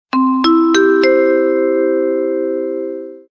Окунитесь в атмосферу аэропорта с коллекцией звуков объявлений и фоновых шумов.
Объявления в аэропорту - Мелодия звукового оповещения